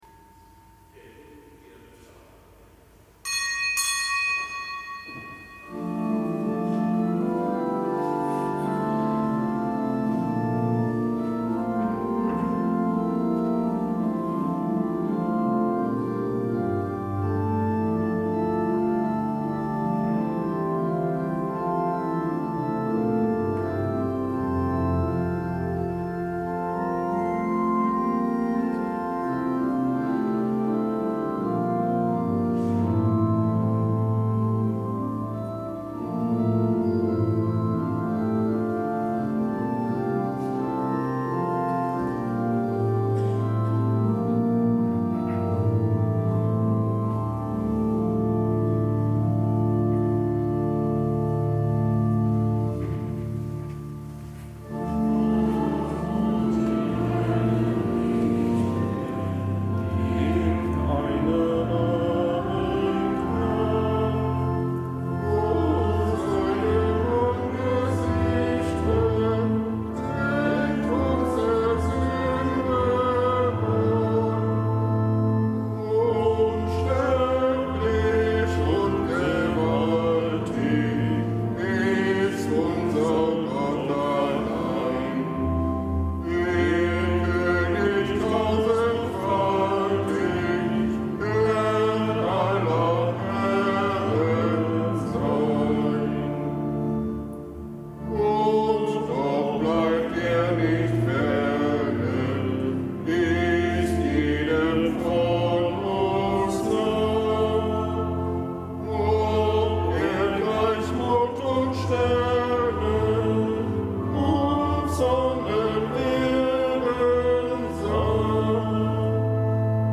Kapitelsmesse am Fest Albert der Große
Kapitelsmesse aus dem Kölner Dom am Fest Albert der Große.